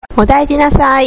慣用句」の項でも触れているとおり、群馬では、医療機関で診察・受診が終わったときに(群馬出身の）医療スタッフからかけられる言葉は「おだいじなさ〜い！」